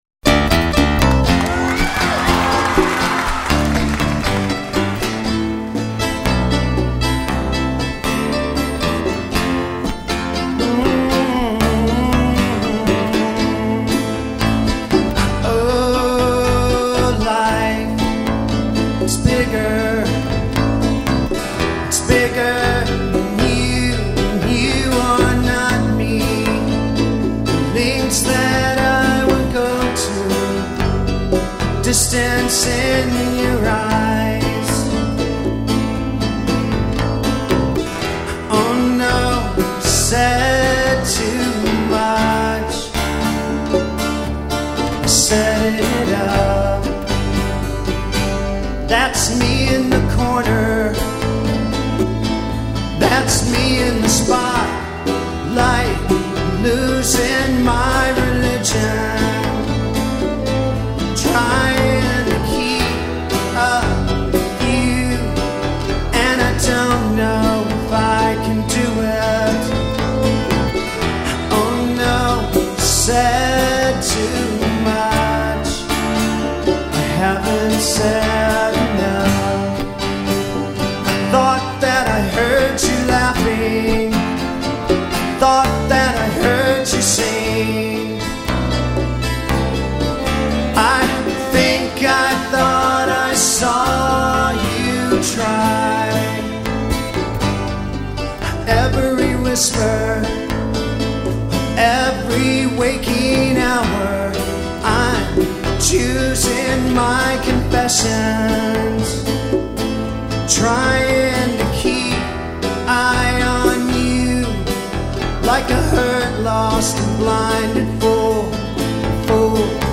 slightly slower all-acoustic performance